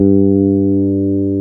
G SUS.wav